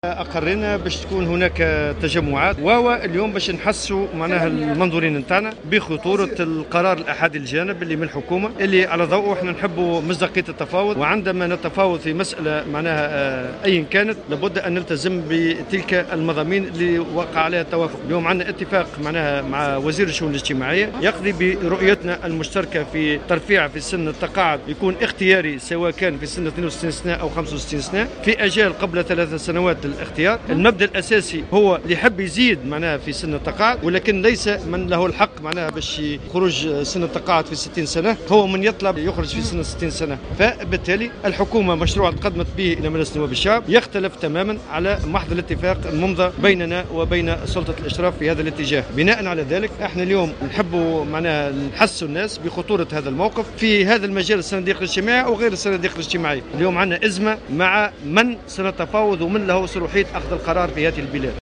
وقال في تصريحات على هامش لقاء شعبي للاتحاد في بلدية بن عروس اليوم السبت إنه تم اقرار عديد التجمعات اثر قرار الترفيع في سن التقاعد على خلاف ما تم الاتفاق بشانه.